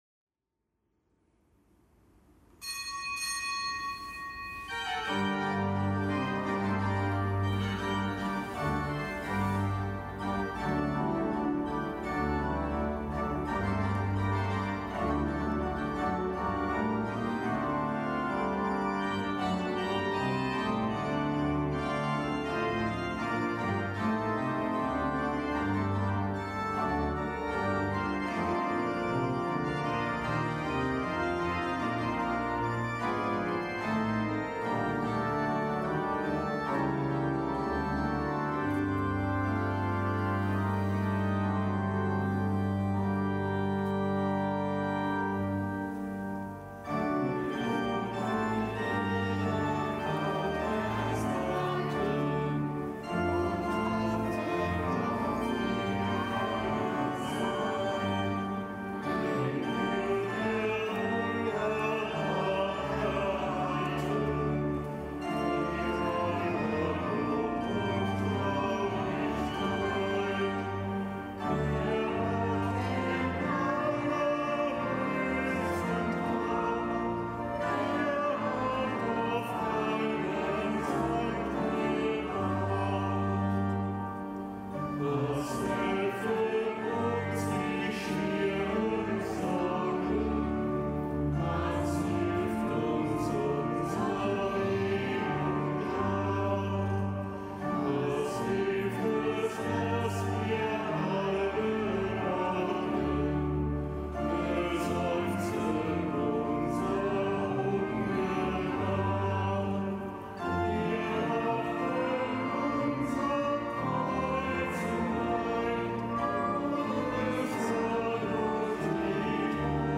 Kapitelsmesse aus dem Kölner Dom am Dienstag der neunzehnten Woche im Jahreskreis.